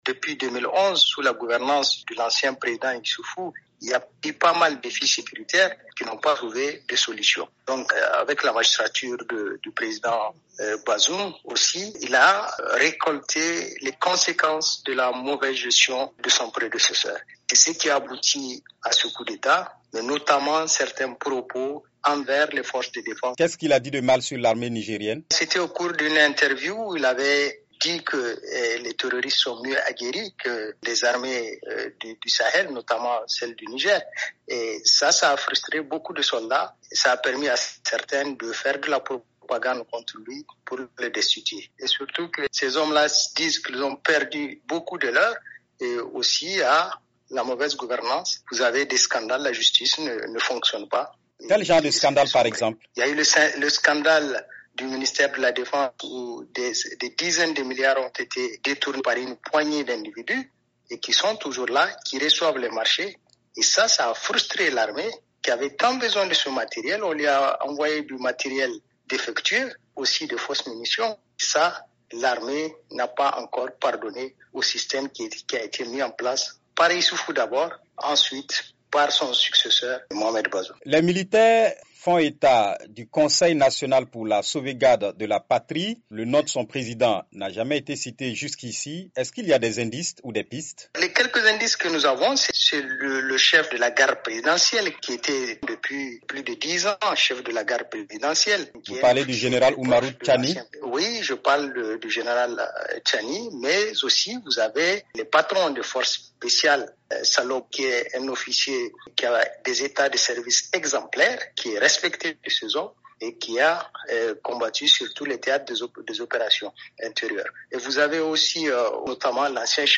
journaliste et consultant, joint à Niamey